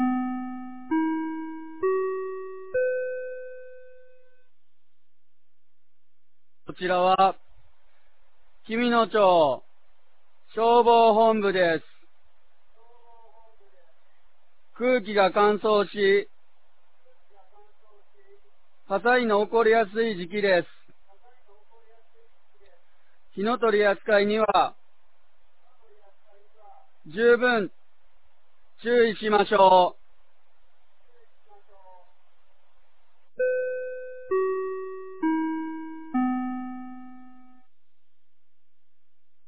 2024年01月13日 16時00分に、紀美野町より全地区へ放送がありました。
放送音声